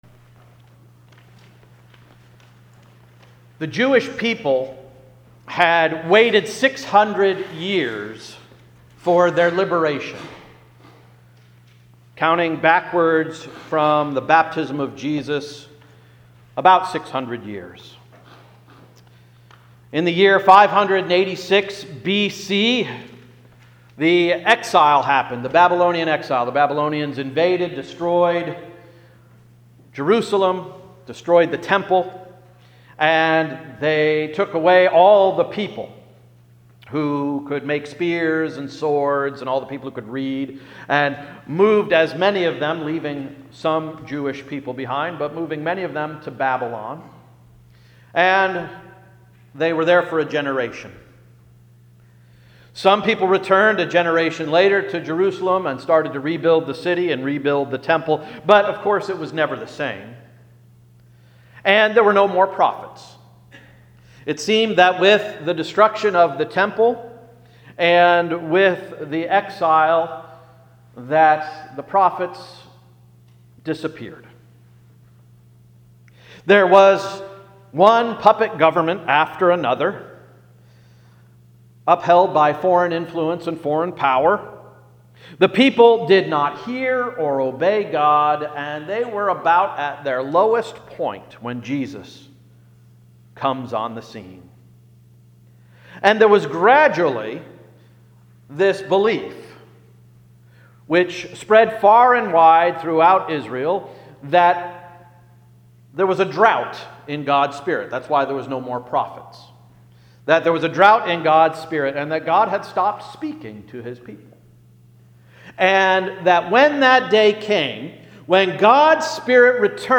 January 15, 2017 Sermon — “Sitting in Time Out”
Welcome to Hill's Church Export, PA